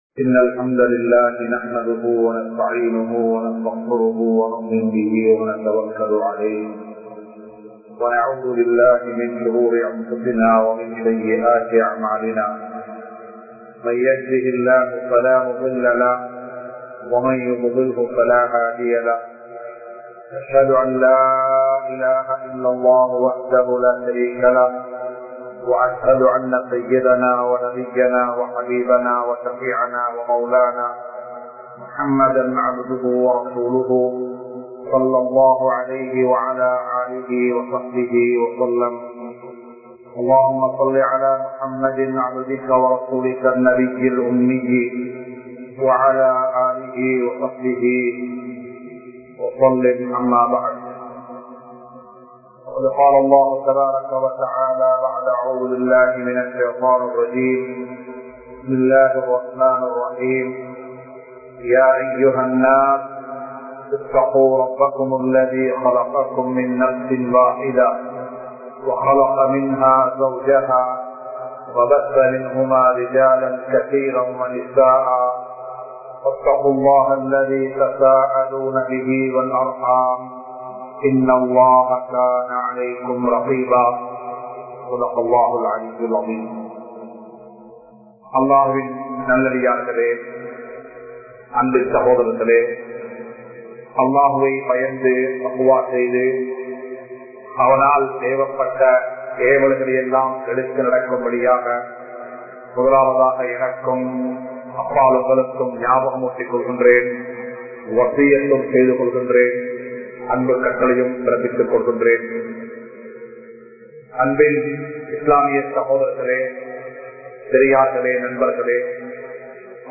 Kudumba Uravai Murikkatheerkal!(குடும்ப உறவை முறிக்காதீர்கள்!) | Audio Bayans | All Ceylon Muslim Youth Community | Addalaichenai